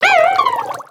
Cri de Viskuse dans Pokémon X et Y.